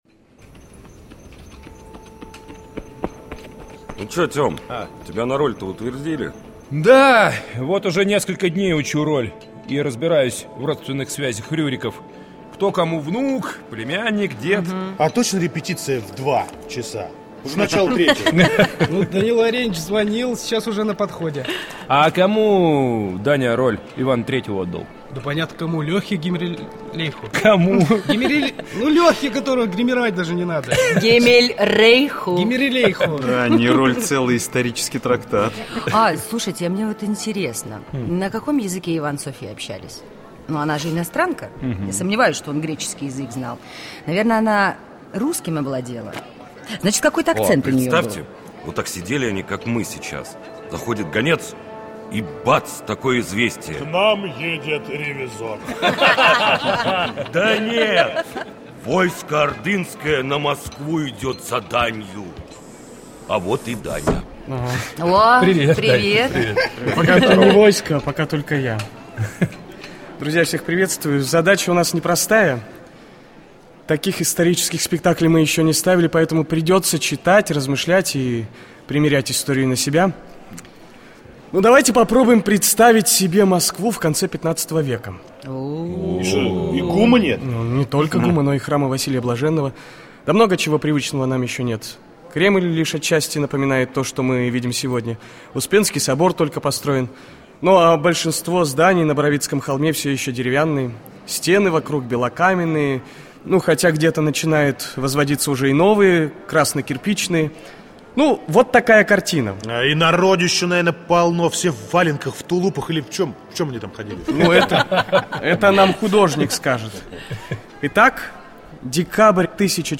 Radiospektakl-Car-i-Bog.mp3